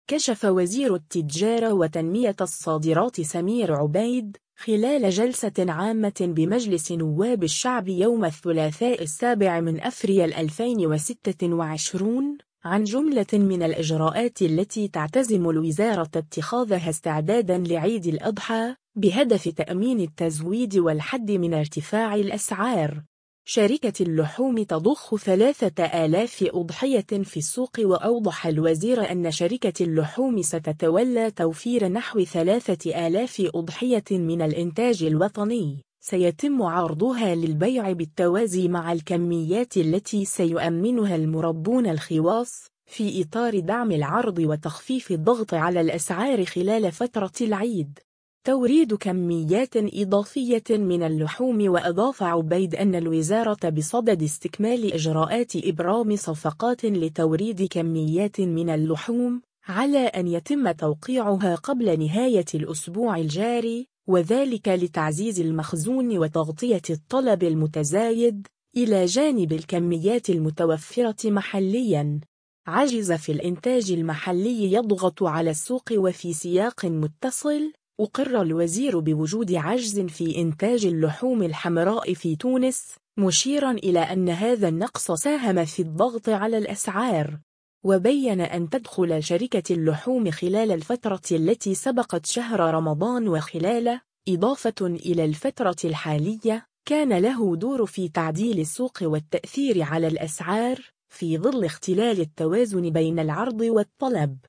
كشف وزير التجارة وتنمية الصادرات سمير عبيد، خلال جلسة عامة بمجلس نواب الشعب يوم الثلاثاء 7 أفريل 2026، عن جملة من الإجراءات التي تعتزم الوزارة اتخاذها استعداداً لعيد الأضحى، بهدف تأمين التزويد والحد من ارتفاع الأسعار.